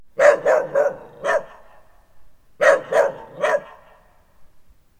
Index of /cianscape/birddataDeverinetal2025/File_origin/Noise-ESC-50/dog